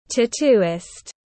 Tattooist /tæˈtuːɪst/